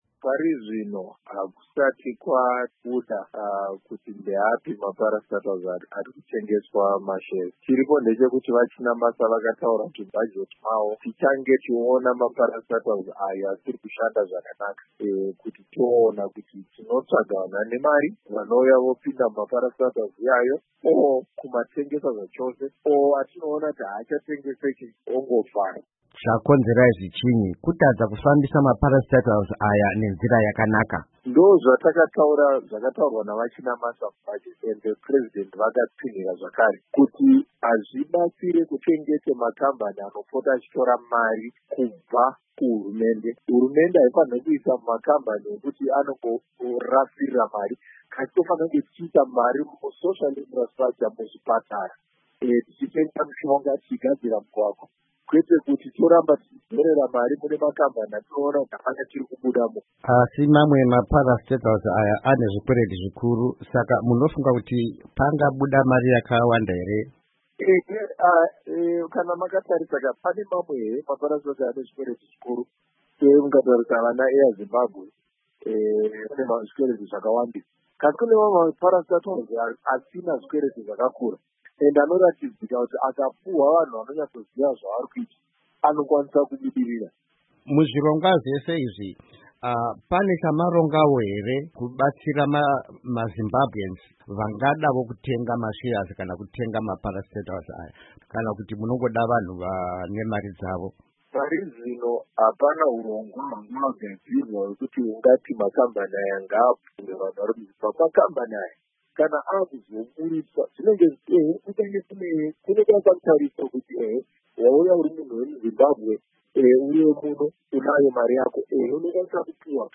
Hurukuro naVaTerence Mukupe